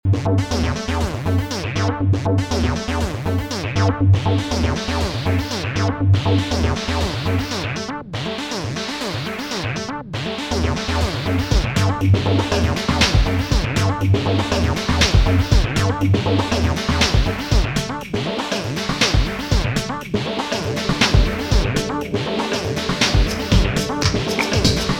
As you can hear, being able to even automate the low pass filter lets you breathe some interesting life into the somewhat generic sounding instruments & drum kits